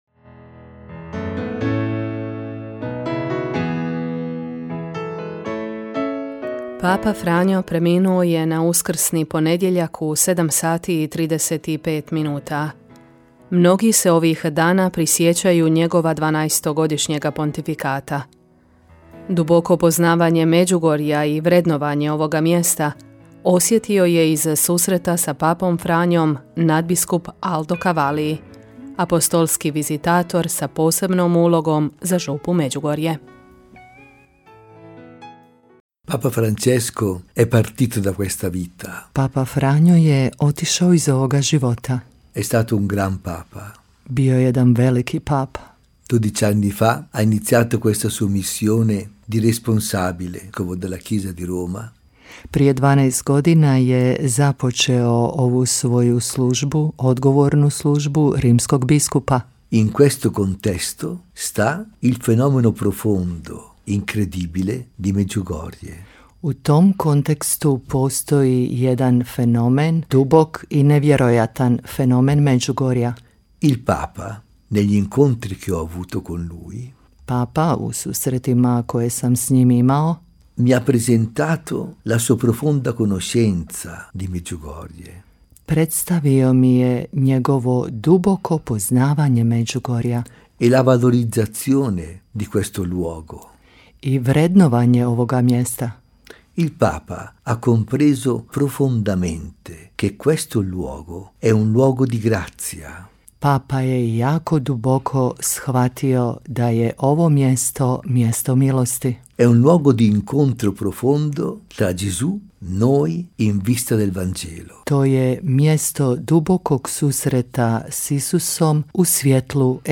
U programu Radiopostaje Mir Međugorje ovih dana opraštamo se od pape Franje, koji je preminuo na Uskrsni ponedjeljak. Sjećamo ga se kroz razgovore s našim sugovornicima koji su s nama podijelili neka sjećanja vezana za papu Franju, na susrete s njim i zapravo sve ono što je on činio i živio. 266. nasljednik apostola Petra, ostat će u sjećanju vjernika diljem svijeta kao svjedok nade i prijatelj svih ljudi.